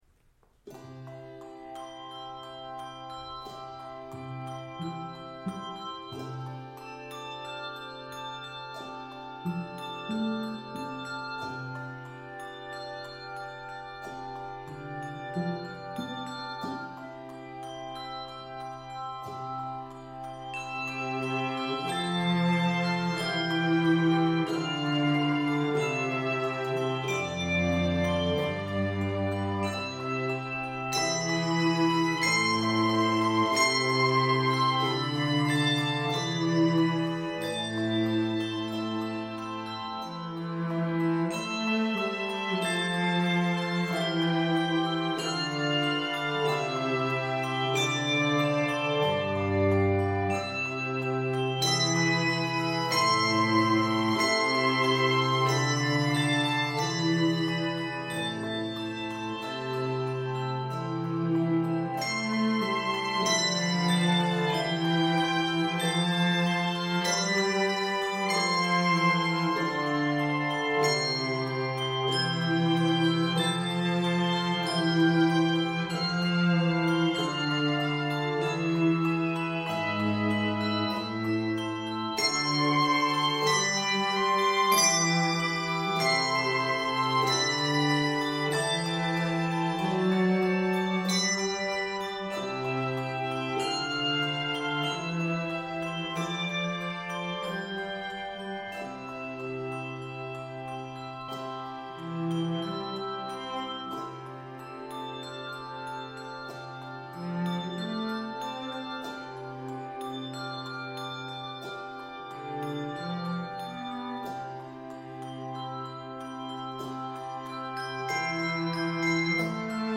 Key of C Major.